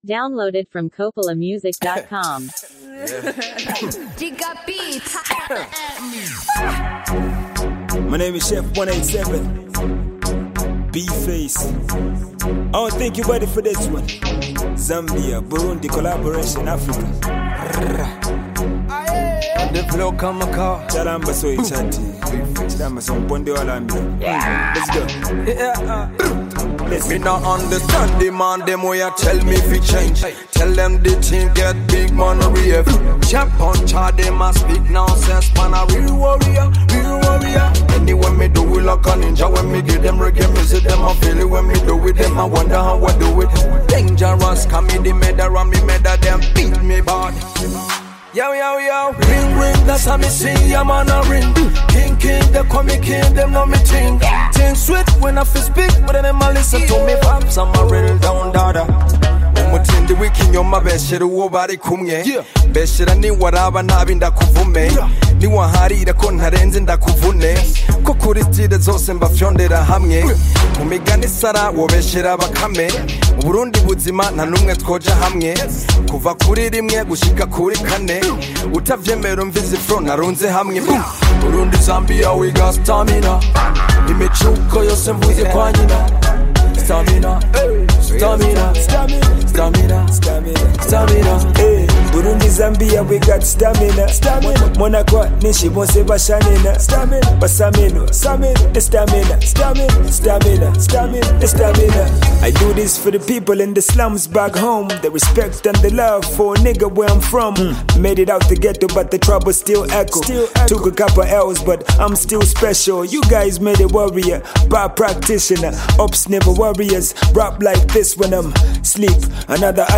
high-energy Zambian tracks
sets the tone with sharp lyrics and a powerful delivery
steps in with his signature smooth yet commanding flow